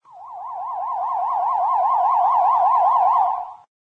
hugeWaveApproching.ogg